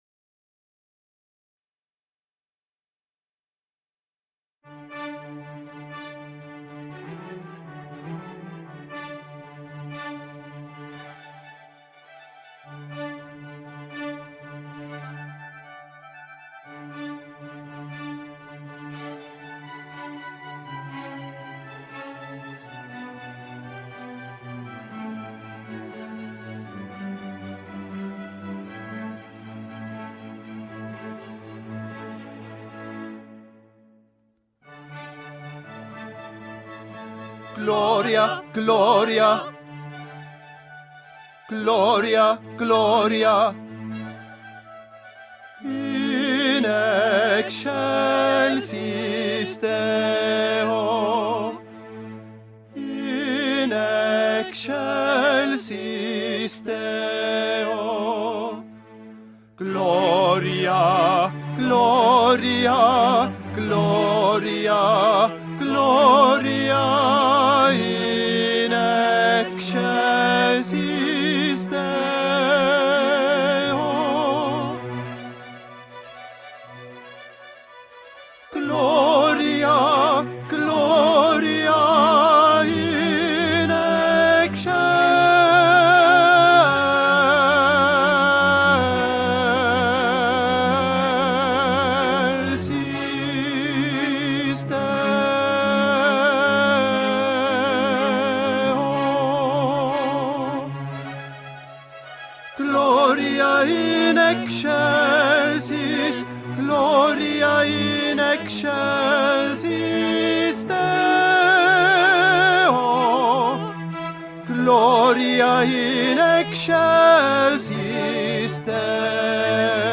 "Gloria_Tenor".
Gloria_Tenor.mp3